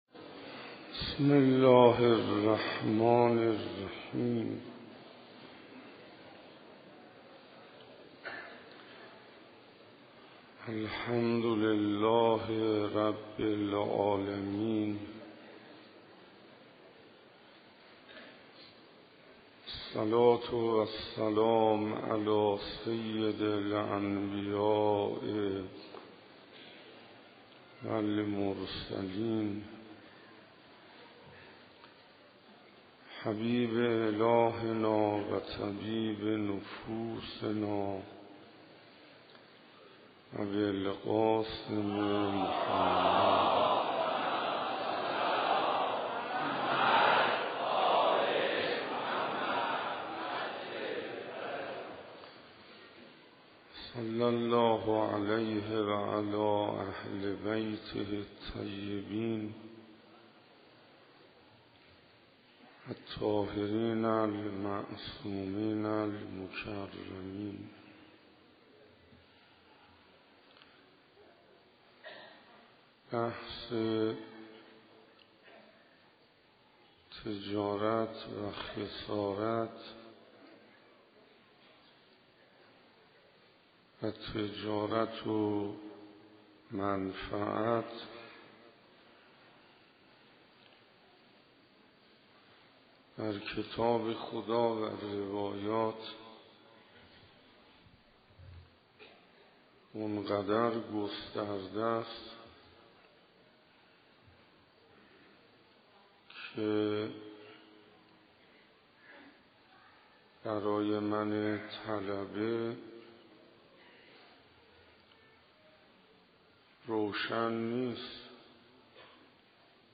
دهه دوم محرم 95 بقعه شیخ نوایی سخنرانی نهم_تجارت و خسارت